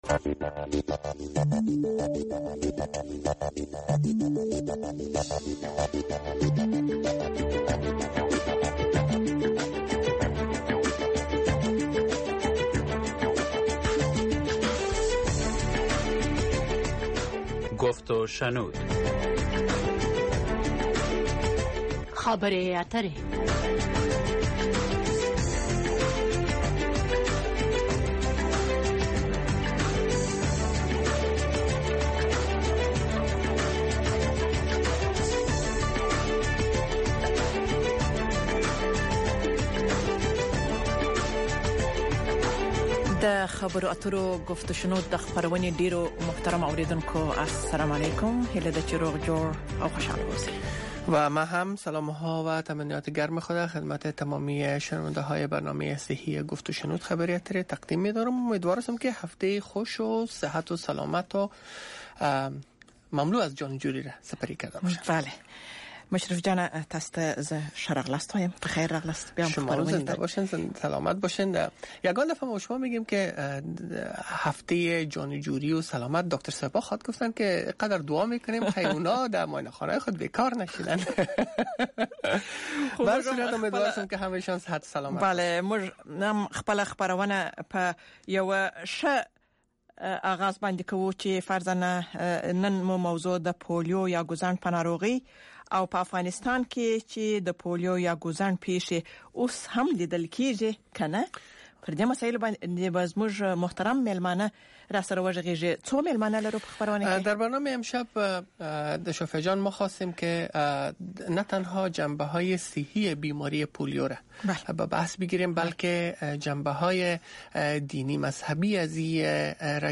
polio call in show